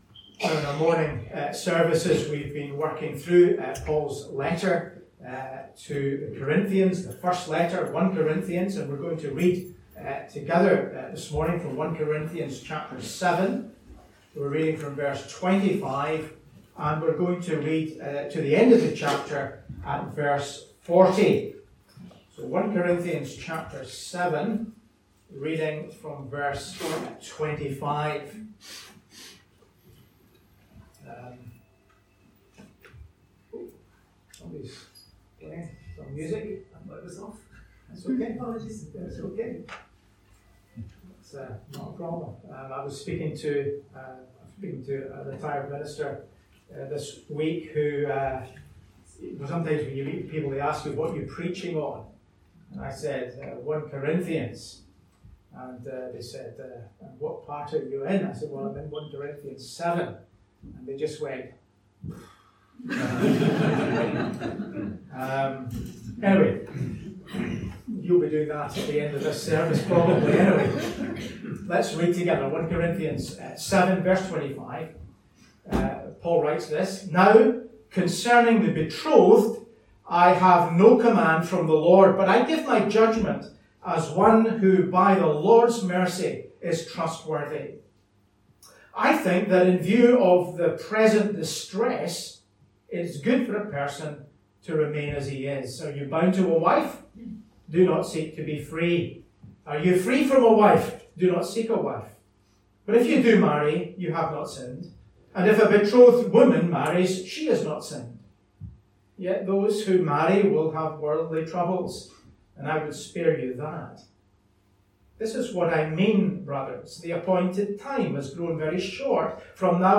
A link to the video recording of the 11:00 am service and an audio recording of the sermon.